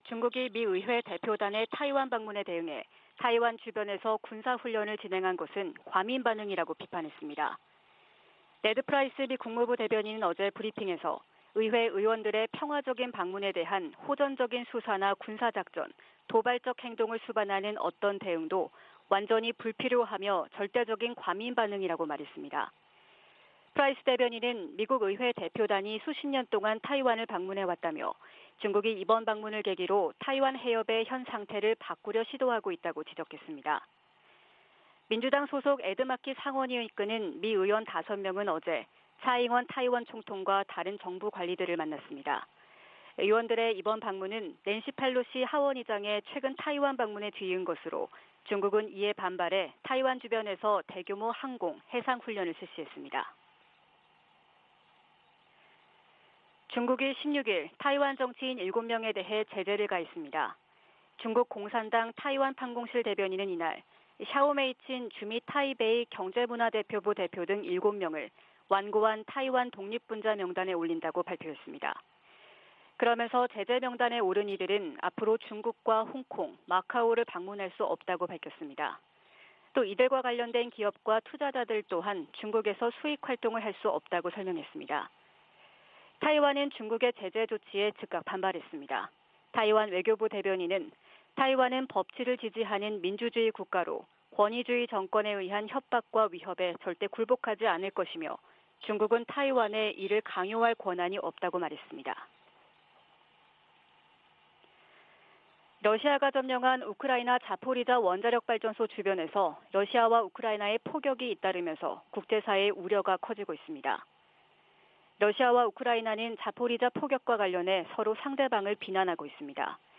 VOA 한국어 '출발 뉴스 쇼', 2022년 8월 17일 방송입니다. 미국과 한국, 일본, 호주, 캐나다 해군이 하와이 해역에서 ‘퍼시픽 드래곤’ 훈련을 진행했습니다. 미 국무부는 한국 윤석열 대통령이 언급한 ‘담대한 구상’과 관련해 북한과 외교의 길을 모색하는 한국 정부를 강력히 지지한다고 밝혔습니다. 에드 마키 미 상원의원이 한국에서 윤석열 대통령과 권영세 통일부 장관을 만나 동맹 강화 방안과 북한 문제 등을 논의했습니다.